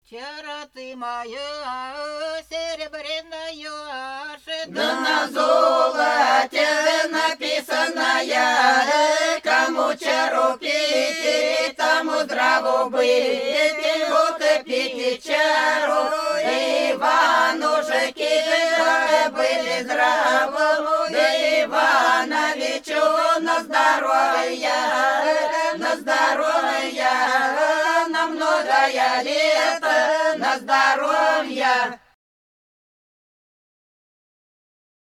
Пролетели все наши года Чара ты моя серебряная – свадебная, величальная (Фольклорный ансамбль села Подсереднее Белгородской области)
15_Чара_ты_моя_серебряная_–_свадебная,_величальная.mp3